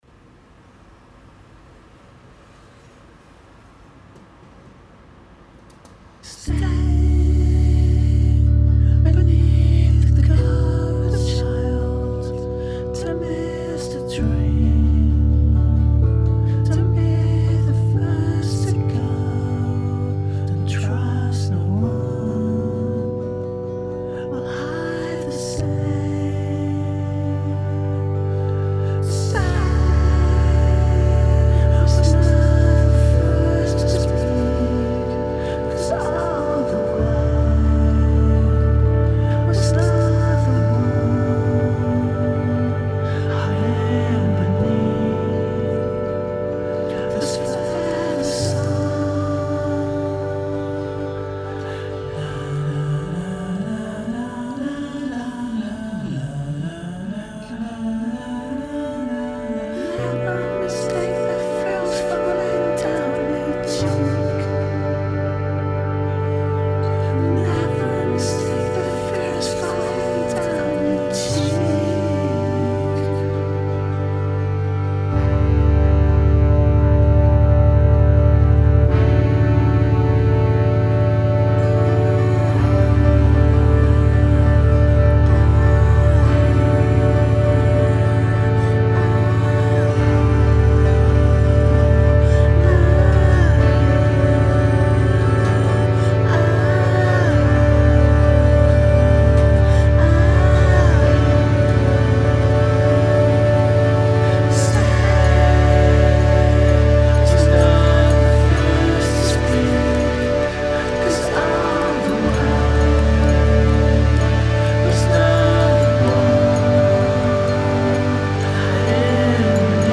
Suoni spesso abbastanza sperimentali e spettrali